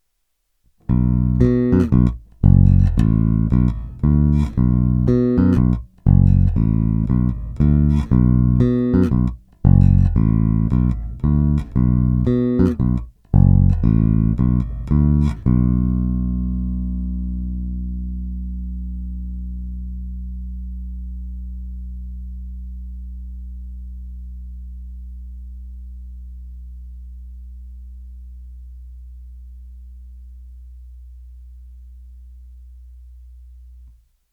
Není-li uvedeno jinak, jsou provedeny rovnou do zvukové karty s korekcemi ve střední poloze, dále jen normalizovány, jinak ponechány bez postprocesingu.
Oba snímače